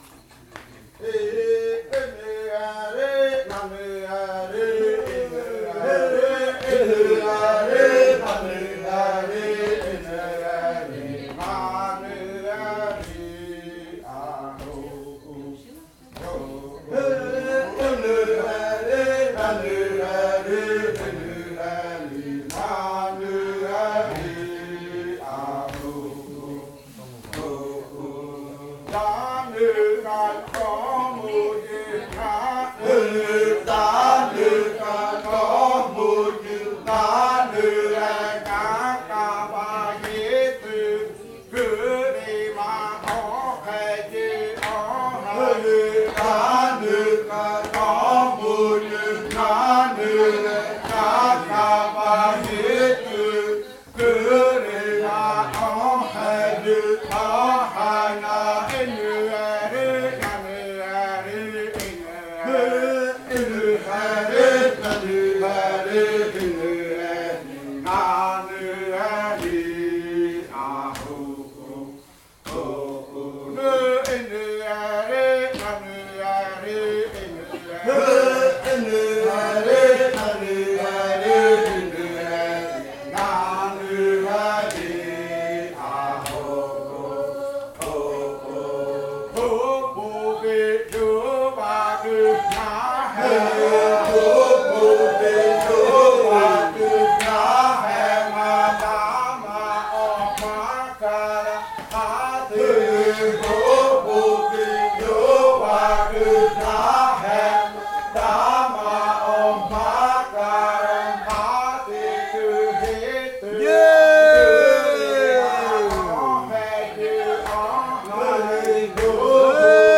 Canto de la variante jimokɨ
Leticia, Amazonas
con el grupo de cantores bailando en Nokaido.
with the group of singers dancing in Nokaido.